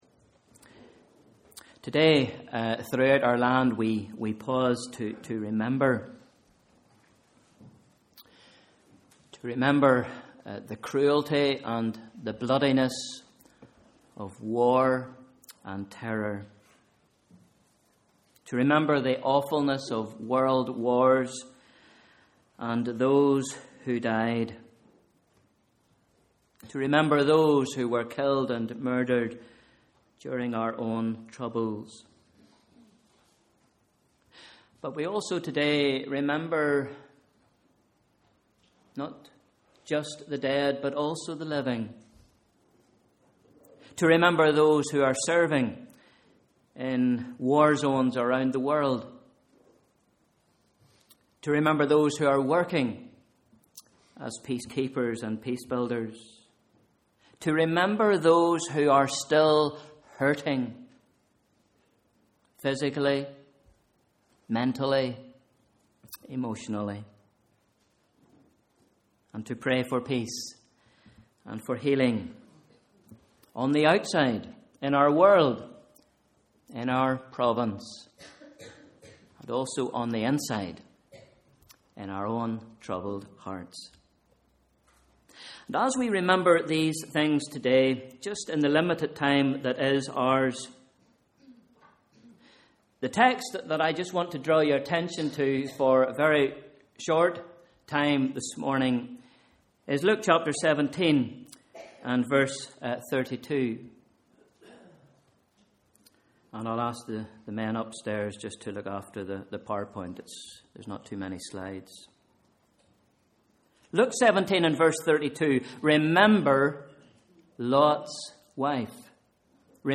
Morning Worship: Sunday 10th December 2013 Bible Reading: Luke 17 v 20-37